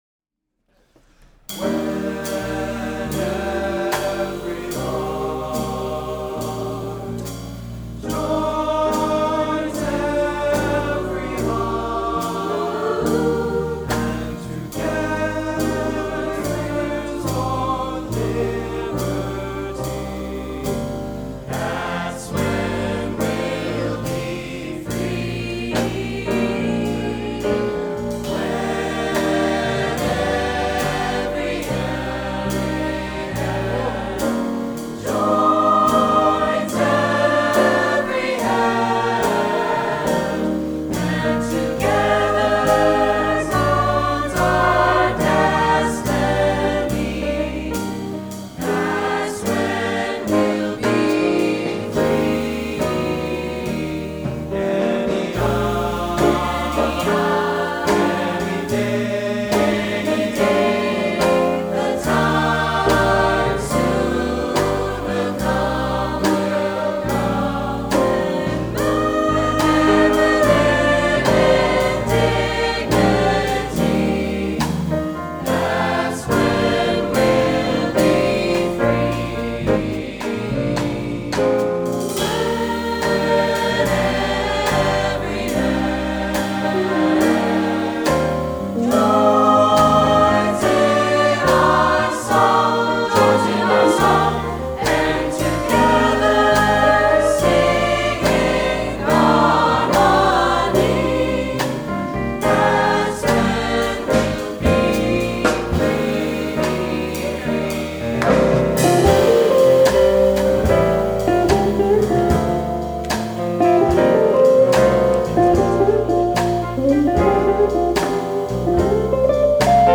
S A T B /rhythm section